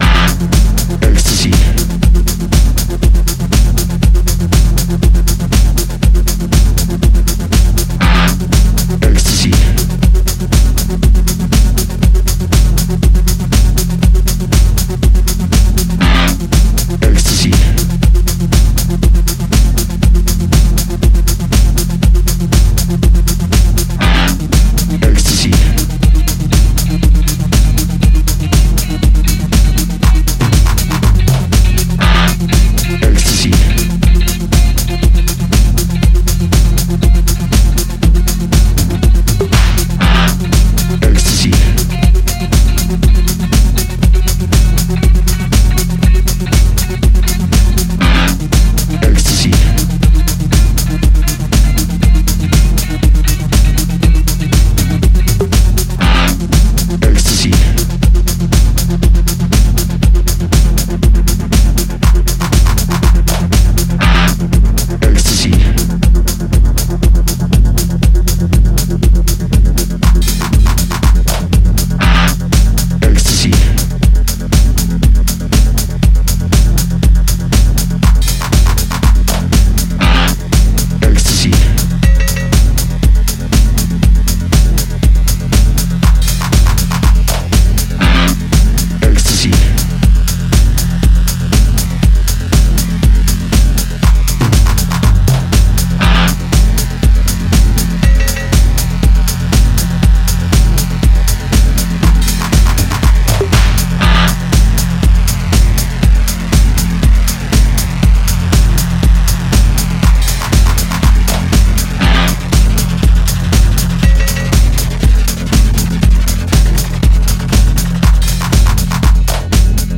Electronics